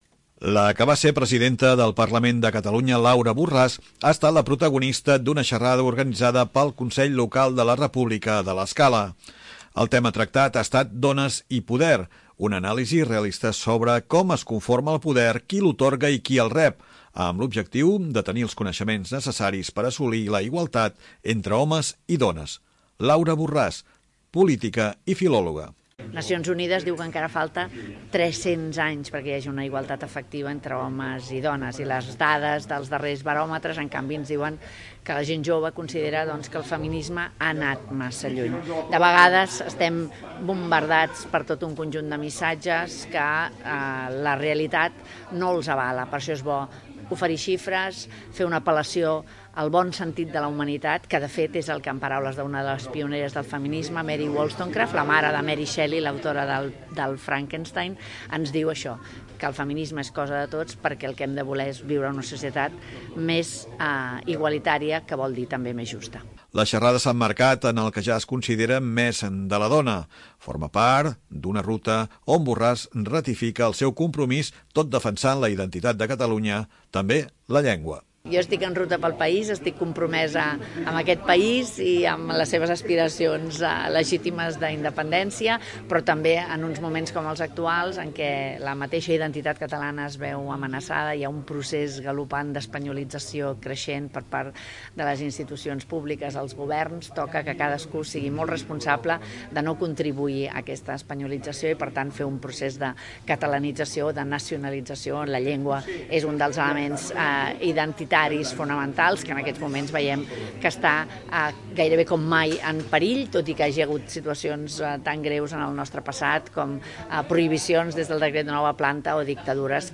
Laura Borràs parla de 'Dones i Poder' en una xerrada al CER davant d'una trentena de persones i organitzada pel Consell de la República.
A la sala del CER hi han assistit una trentena de persones.